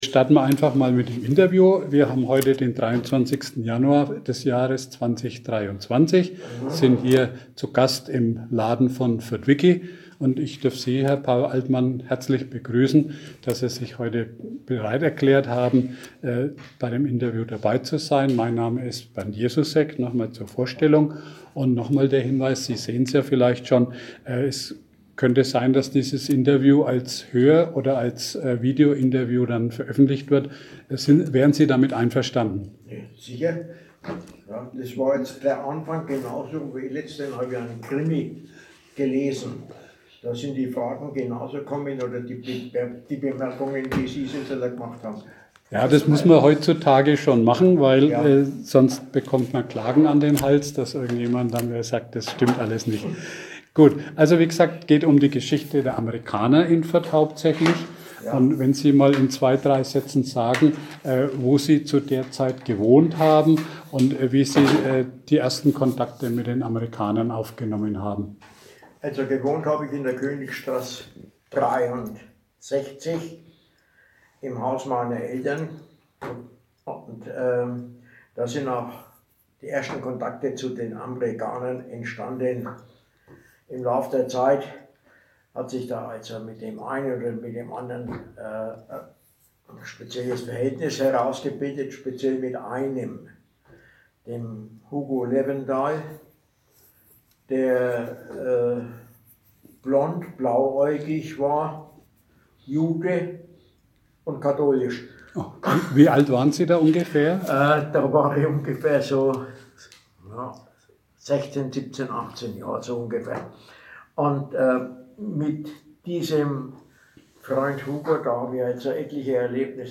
Zeitzeugenberichte